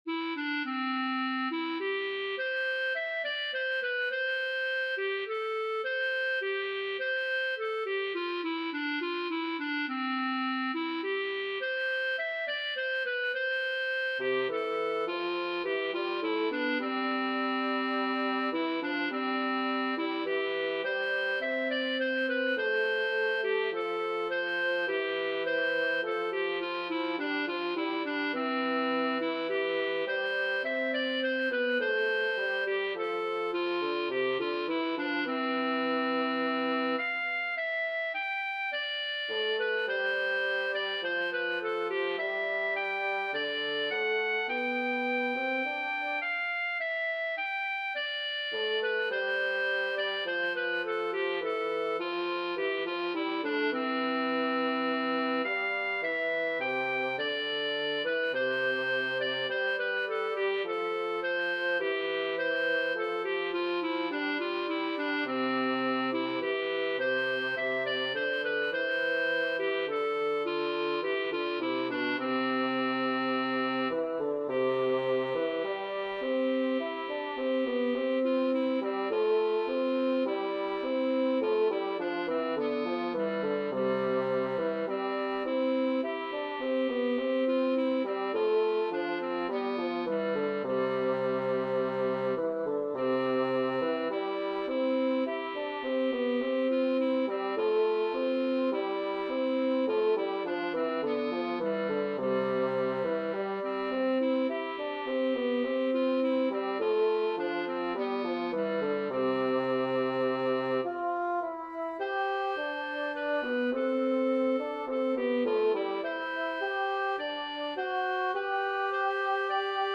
Mixed Woodwind & String Ensembles
Clarinet, Bassoon